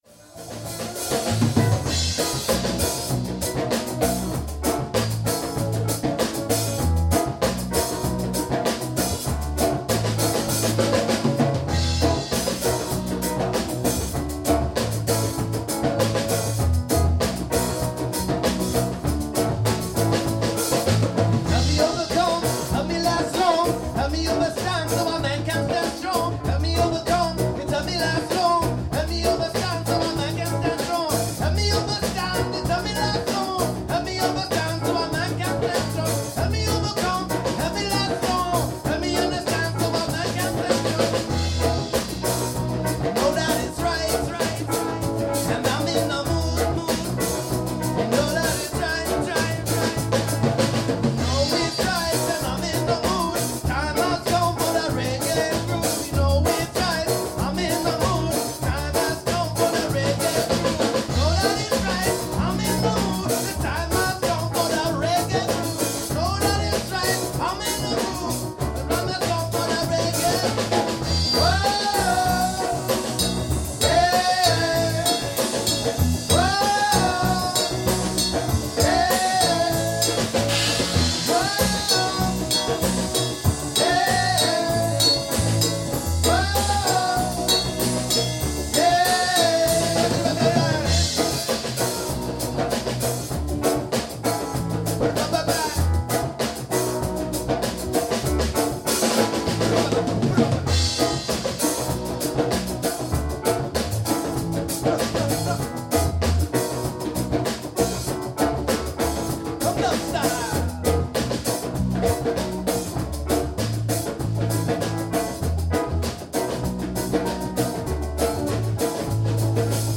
Reggae Grooves
Reggae_Grooves.mp3